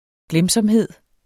glemsomhed substantiv, fælleskøn Bøjning -en, -er, -erne Udtale [ ˈglεmsʌmˀˌheðˀ ] Betydninger tilbøjelighed til at glemme ting, aftaler osv.